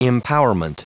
Prononciation du mot empowerment en anglais (fichier audio)
Prononciation du mot : empowerment